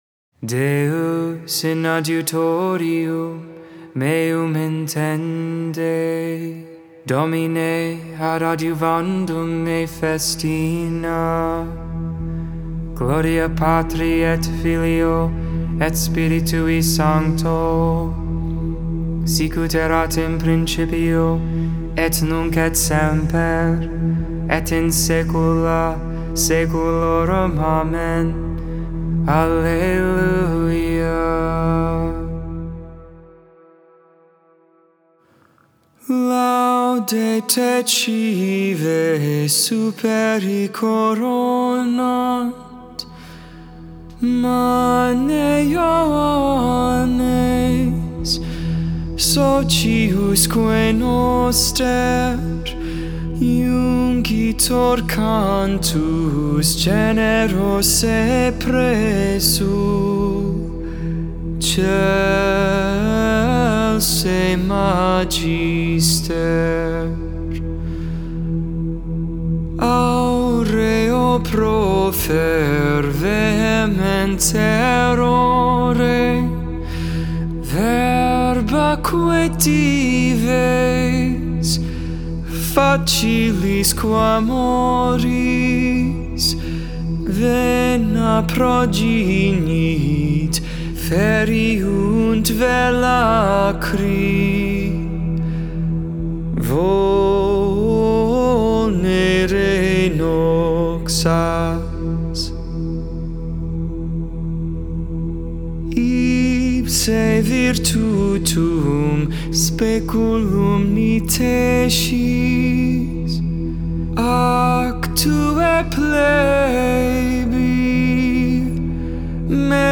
Vespers, Evening Prayer for the 24th Monday in Ordinary Time, September 13th, 2021.